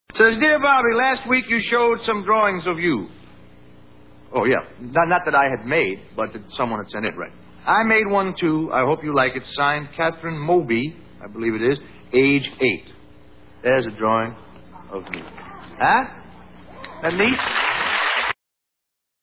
Below are sound files, from his 1973 NBC varitey show, as he shares